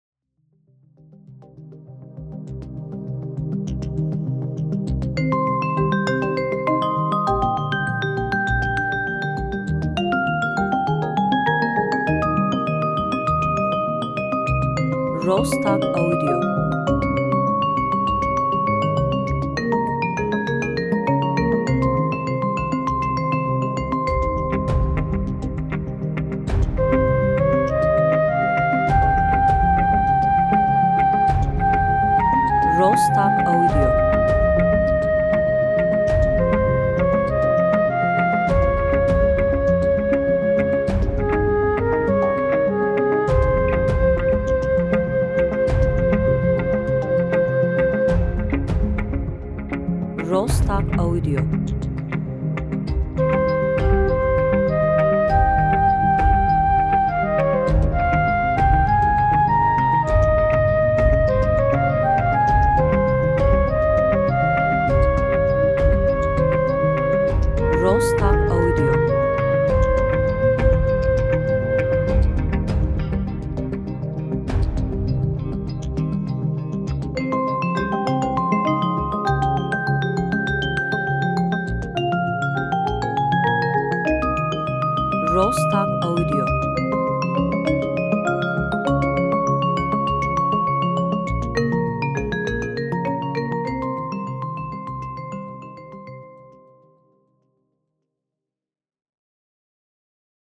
enstrümantal